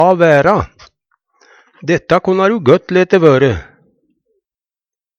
la vera - Numedalsmål (en-US)